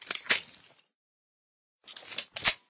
trachelium_reload.ogg